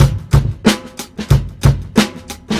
• 92 Bpm Drum Beat C# Key.wav
Free drum loop sample - kick tuned to the C# note. Loudest frequency: 1017Hz
92-bpm-drum-beat-c-sharp-key-ws0.wav